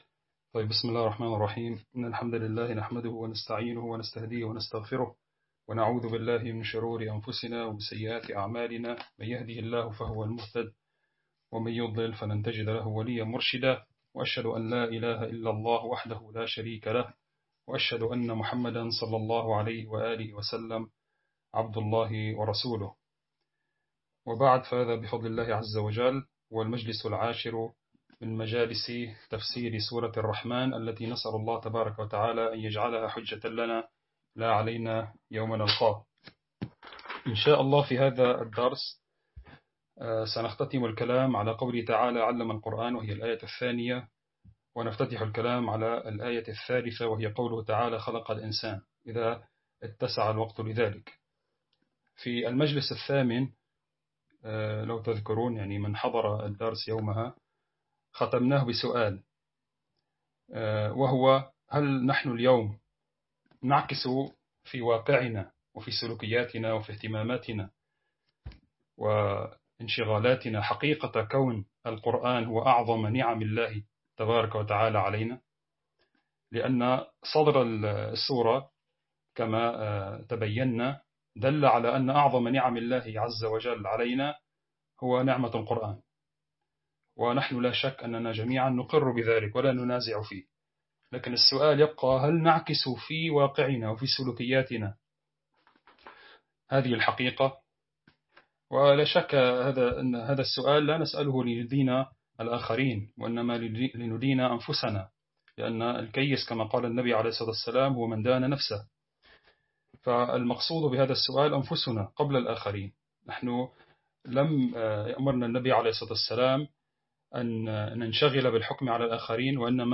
درس عام online